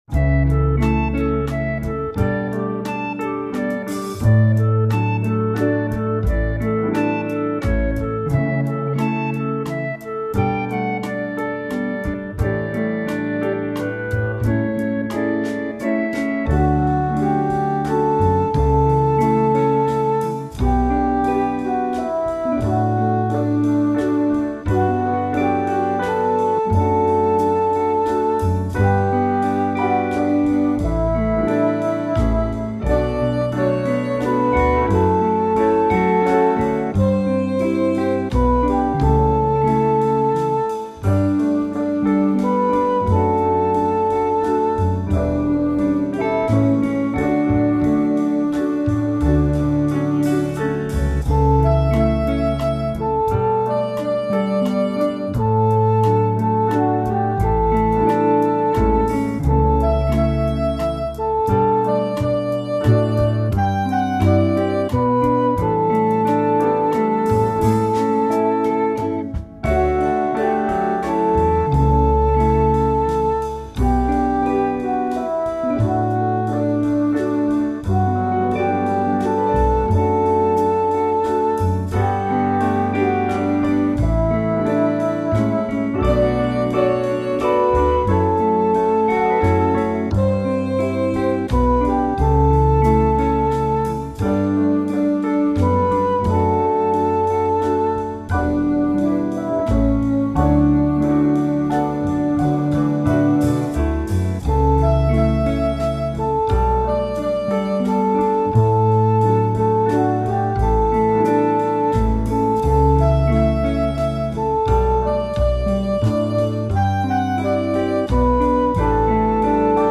this is a gentle communion song based on the Emmaus pericope
This is a very engaging tune, but the verses need a cantor.